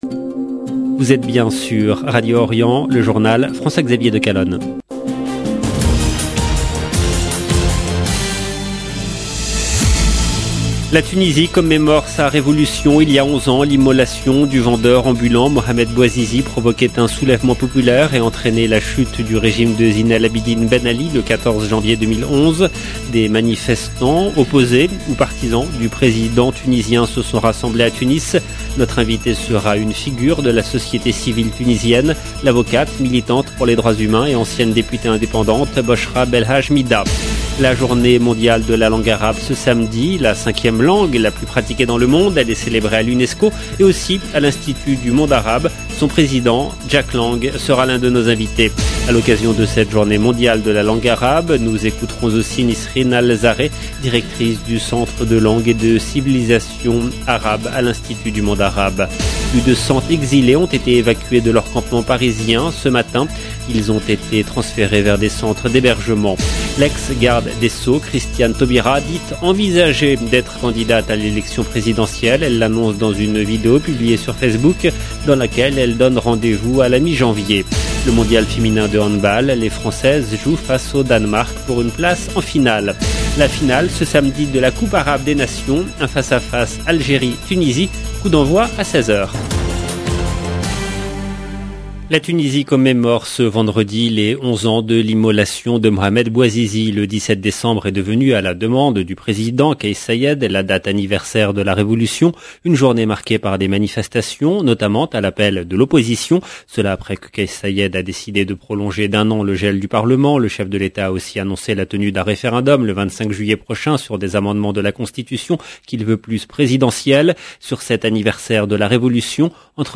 Son président Jack Lang sera l’un de nos invités.